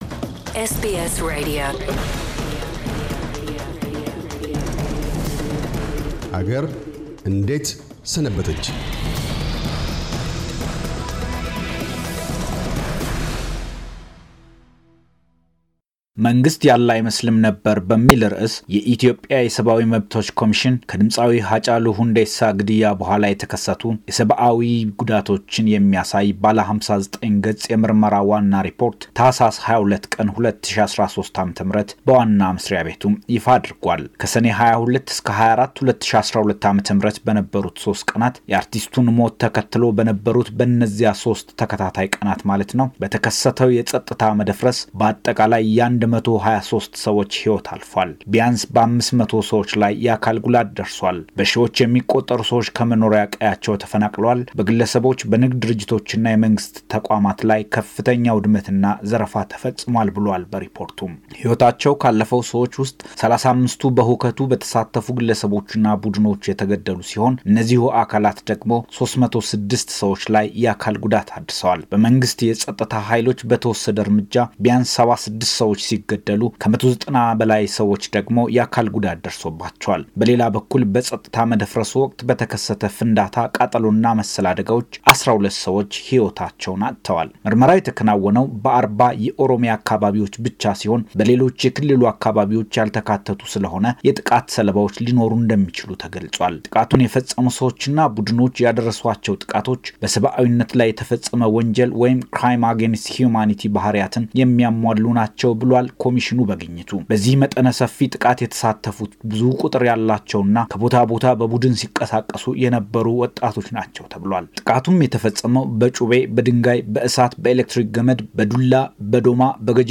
አገርኛ ሪፖርት፤ ዶ/ር ዳንኤል በቀለ፤ የኢትዮጵያ ሰብዓዊ መብቶች ኮሚሽን ዋና ኮሚሽነር ኢትዮጵያ ውስጥ በሰብዓዊነት ላይ የተፈጸሙ ጥቃቶችን አስመልክቶ ሰሞኑን በኮሚሽኑ ስም ይፋ ያደረጉትን “መንግሥት ያለ አይመስልም ነበር” ሪፖርት ነቅሶ ይዳስሳል።